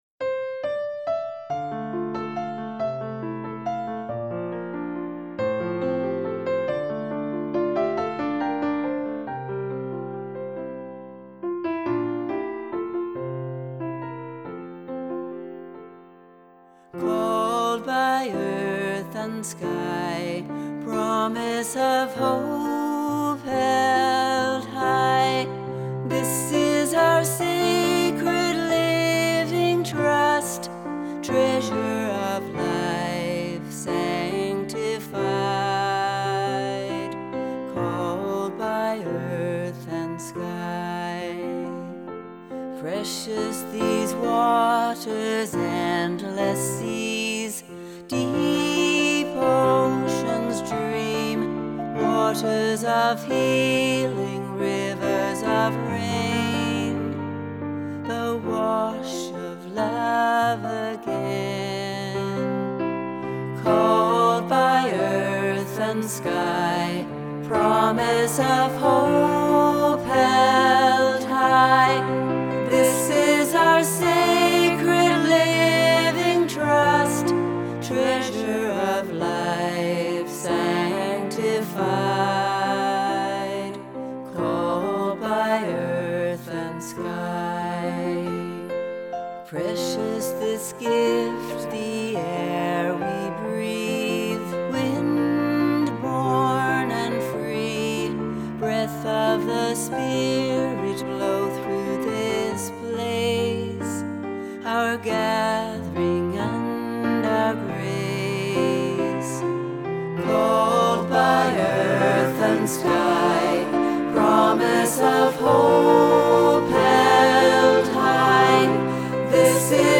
contemporary hymn